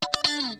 7TH HIT   -R.wav